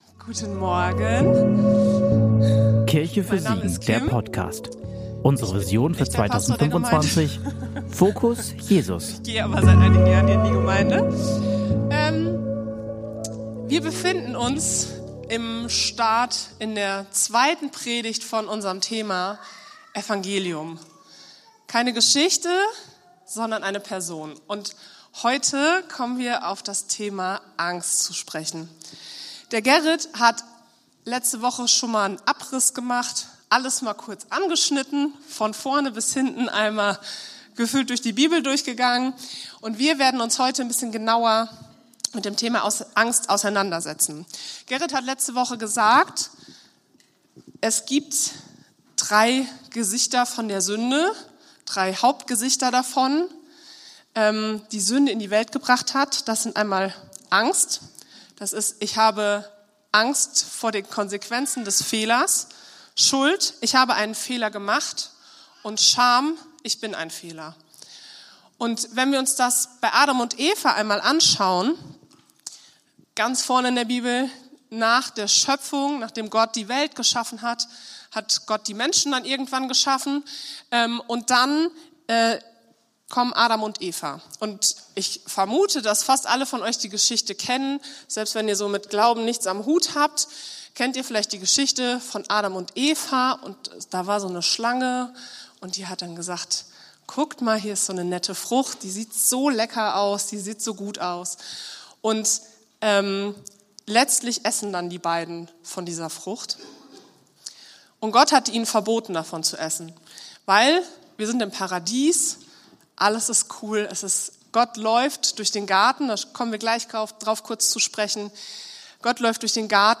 Predigt vom 19.10.2025 in der Kirche für Siegen